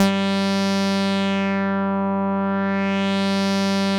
Index of /90_sSampleCDs/Trance_Explosion_Vol1/Instrument Multi-samples/Wasp Dark Lead
G3_wasp_dark_lead.wav